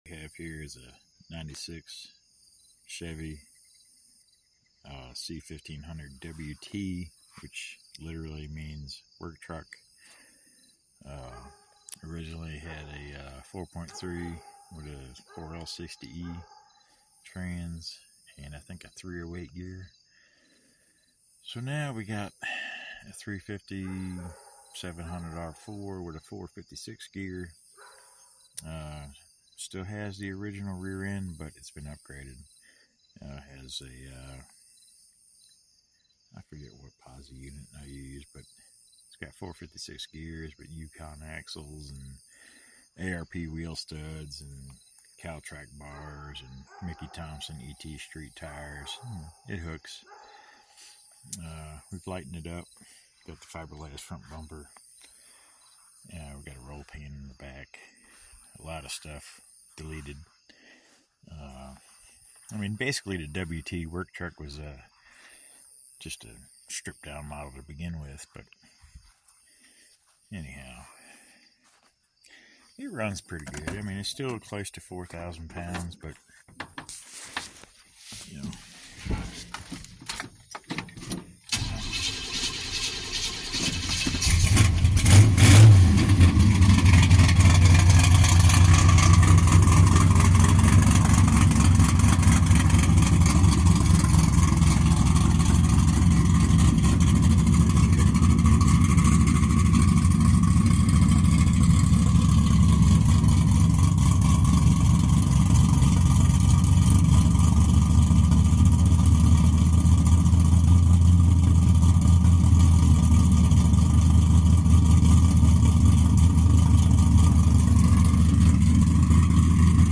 chevy c1500 shitbox ....retired work sound effects free download